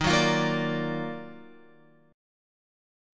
EbmM9 chord